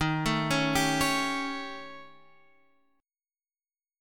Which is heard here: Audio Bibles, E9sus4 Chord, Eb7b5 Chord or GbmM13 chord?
Eb7b5 Chord